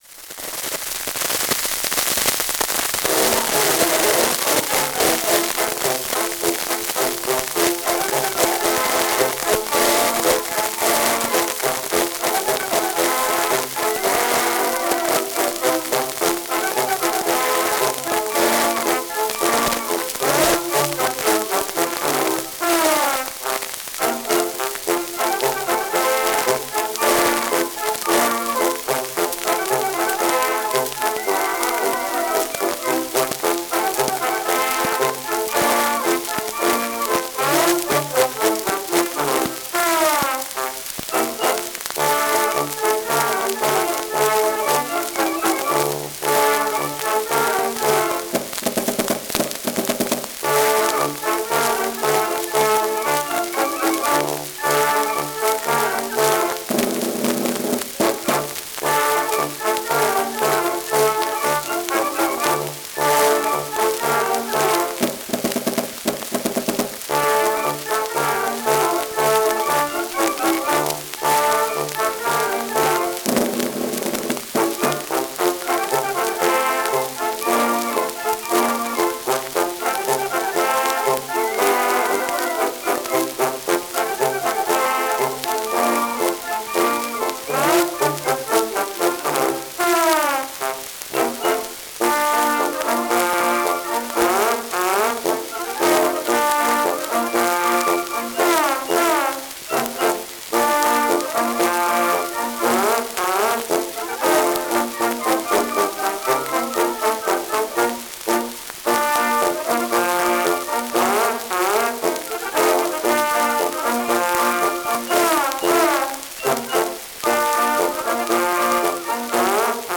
Noisy+bill.mp3